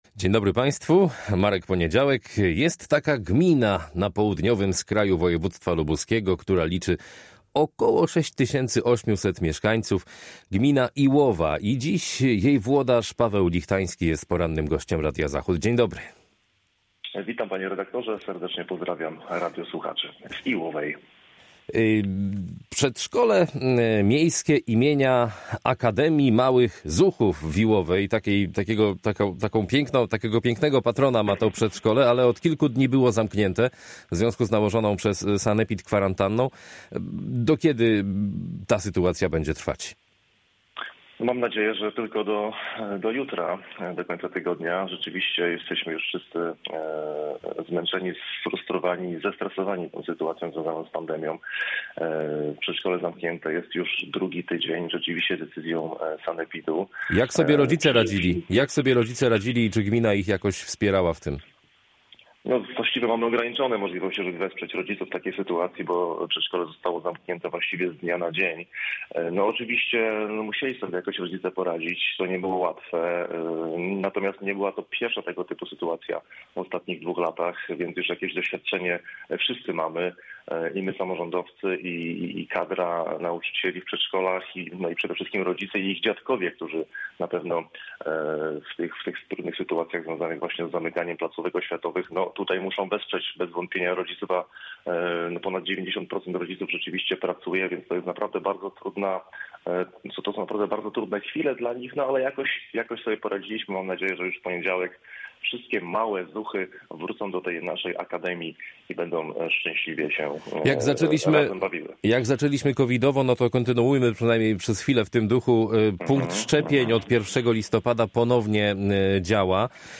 Paweł Lichtański, burmistrz Iłowej